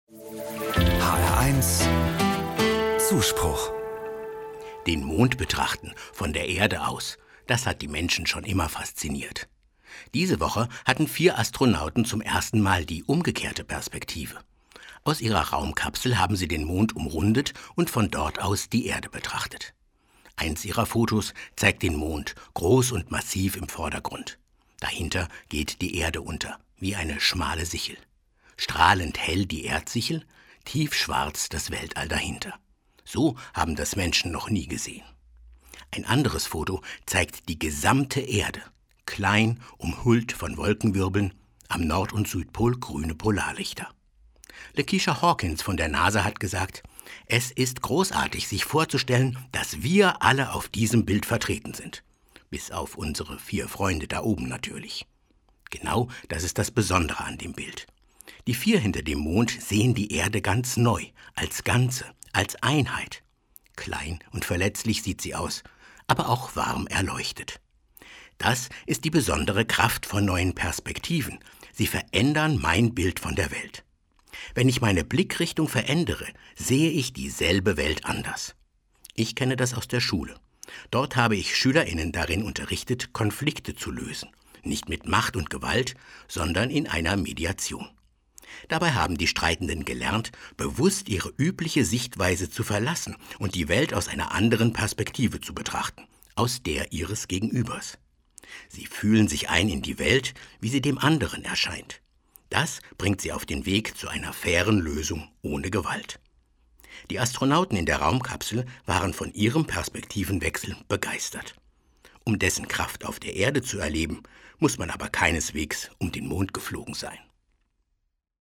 Eine Sendung von